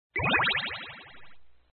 audio_exit_room.mp3